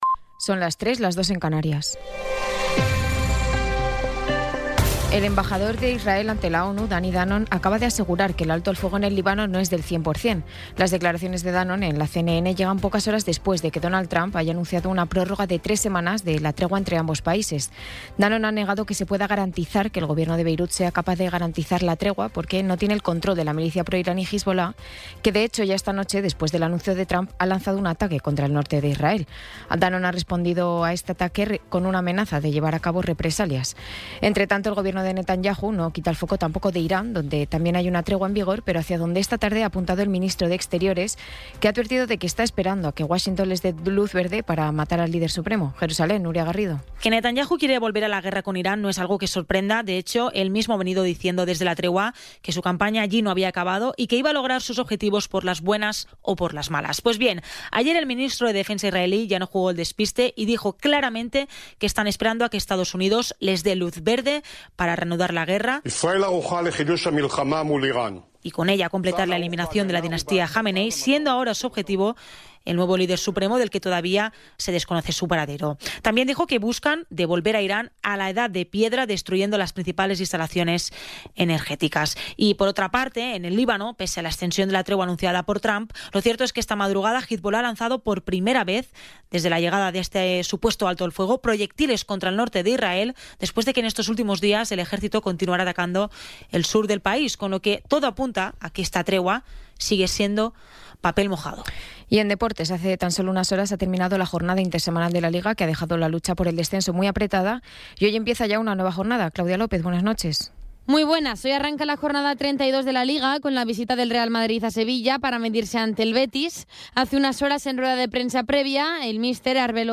Resumen informativo con las noticias más destacadas del 24 de abril de 2026 a las tres de la mañana.